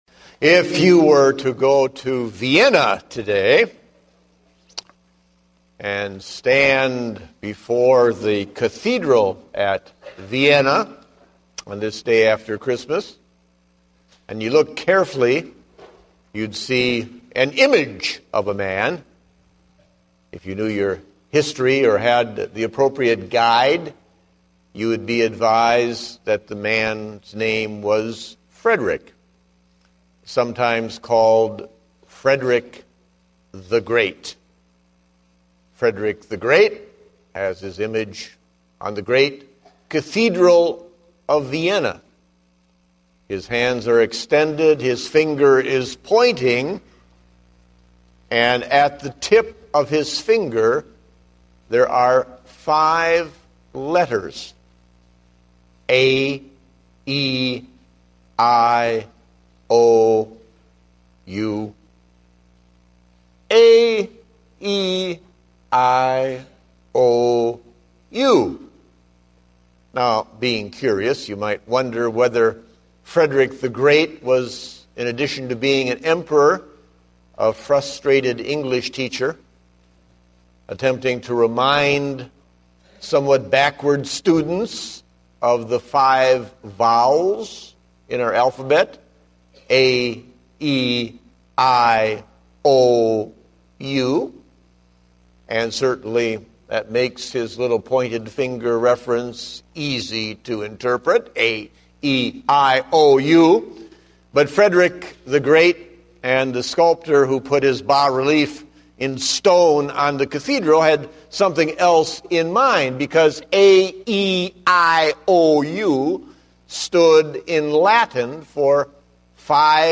Date: December 26, 2010 (Morning Service)